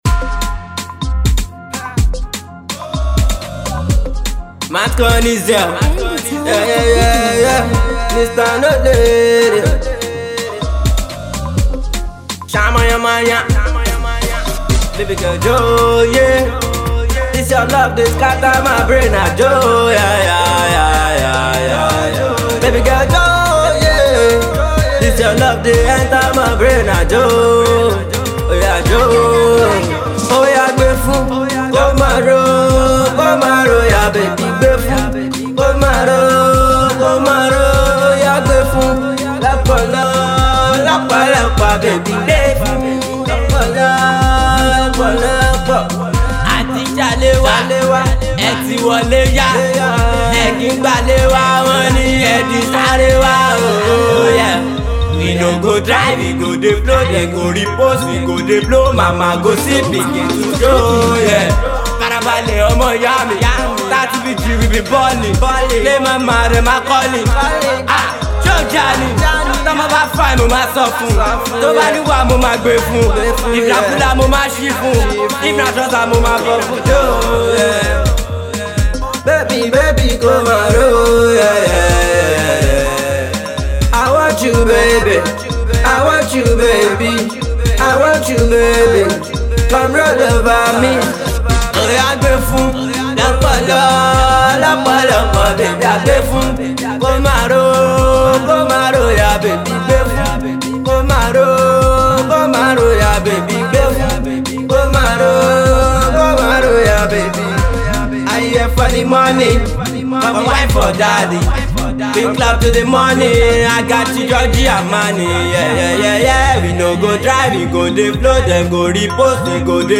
combination of both rap and sing